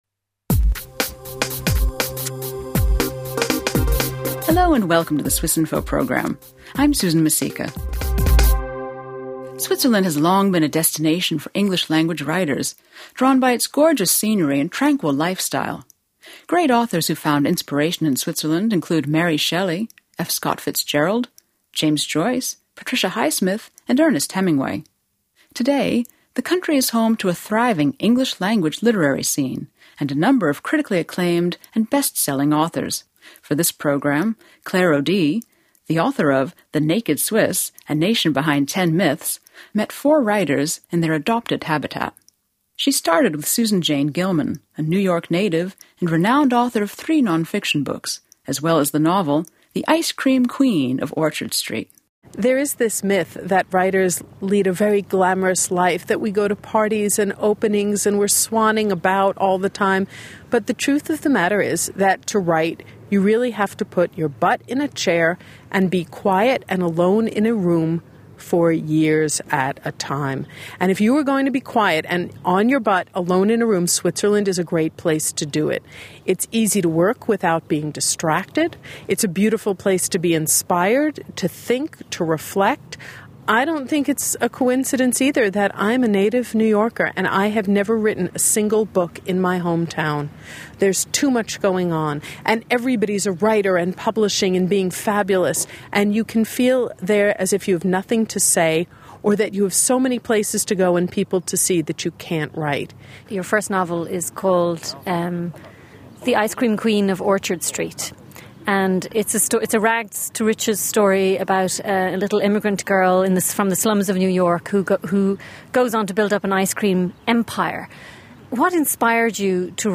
Conversation with four English-speaking authors